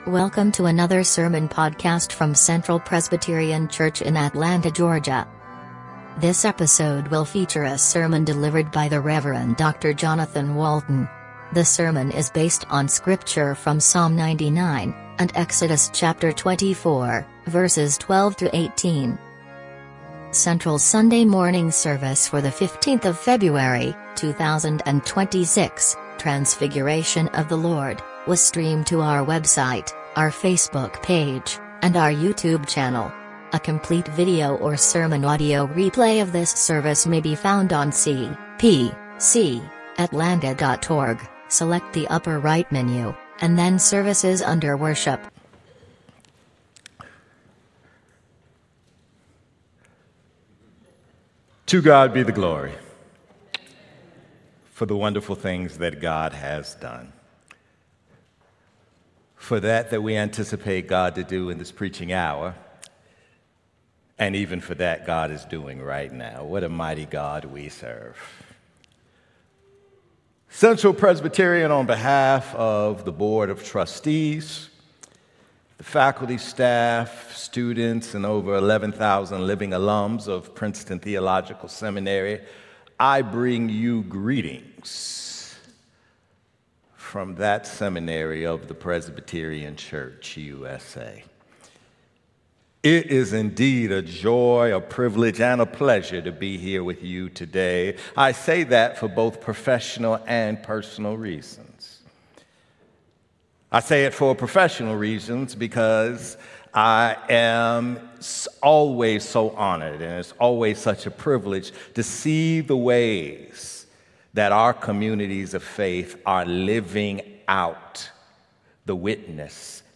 Sermon Audio:
Service Type: Sunday Sermon